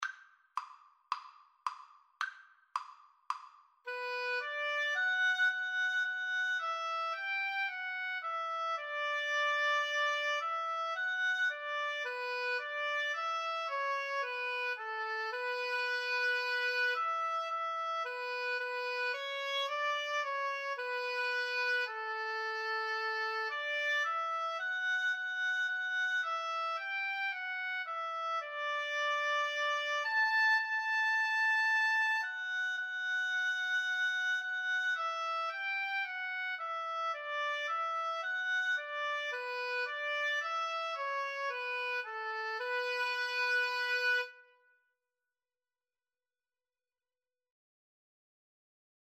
B minor (Sounding Pitch) (View more B minor Music for Oboe-Cello Duet )
Gently Flowing = c. 110
4/4 (View more 4/4 Music)
Traditional (View more Traditional Oboe-Cello Duet Music)